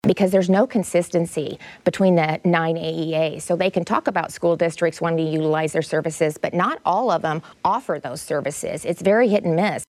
REYNOLDS MADE HER COMMENTS DURING TAPING OF THE “IOWA PRESS” PROGRAM FOR FRIDAY ON IOWA P-B-S.